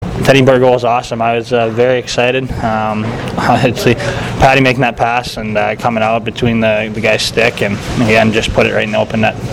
After the game I was in a full sprint to get my story up – get to and through interviews fast – all before Gavin DeGraw’s concert drowned out the BC with loud noises.